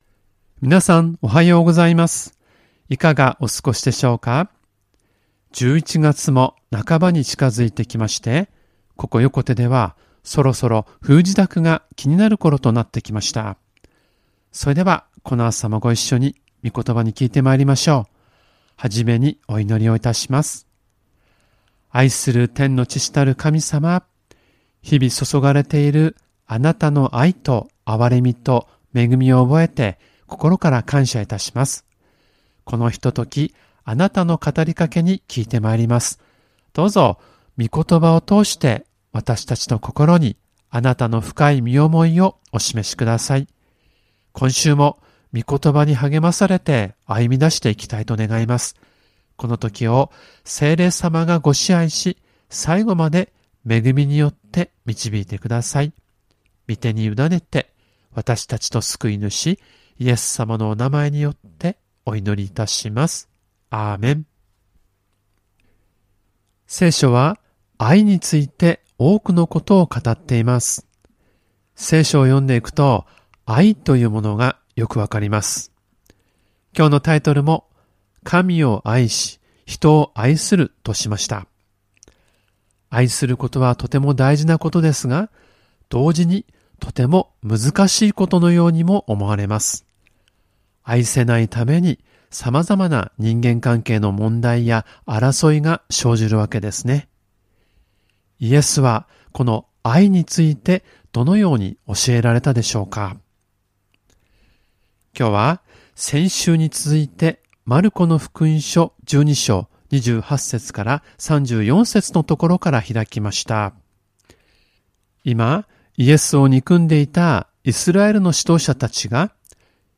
●主日礼拝メッセージ（赤文字をクリックするとメッセージが聴けます。MP3ファイル）